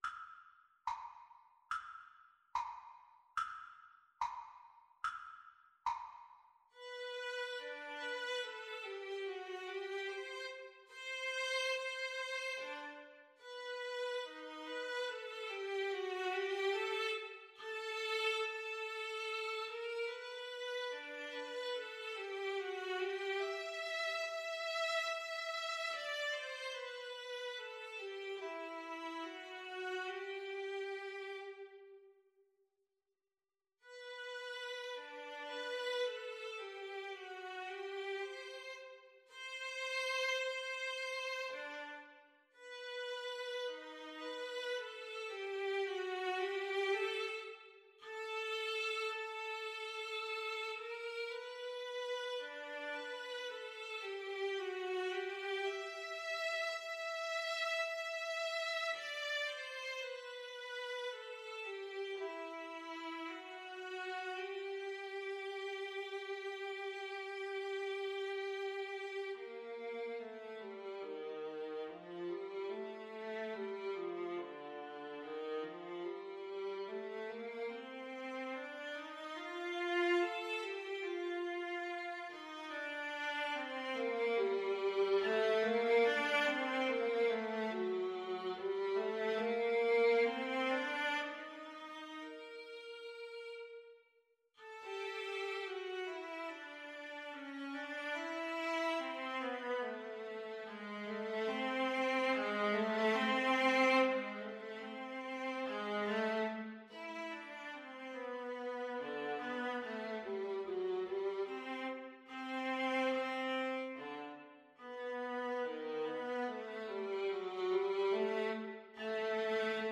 Play (or use space bar on your keyboard) Pause Music Playalong - Player 1 Accompaniment reset tempo print settings full screen
G major (Sounding Pitch) (View more G major Music for Viola Duet )
Andantino =72 (View more music marked Andantino)
Viola Duet  (View more Intermediate Viola Duet Music)